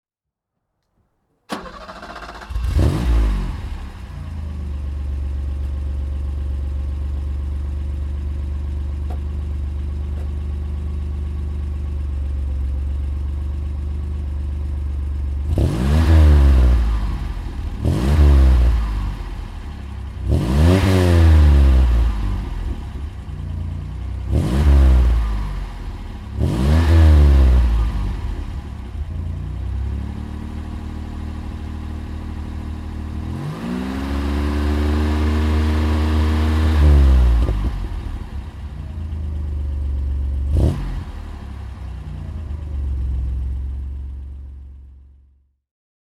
Ford Capri II 2.3 S May-Turbo (1977) - Starten und Leerlauf
Ford_Capri_II_1977.mp3